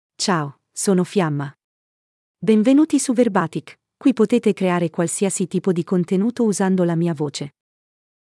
FiammaFemale Italian AI voice
Fiamma is a female AI voice for Italian (Italy).
Voice sample
Listen to Fiamma's female Italian voice.
Female
Fiamma delivers clear pronunciation with authentic Italy Italian intonation, making your content sound professionally produced.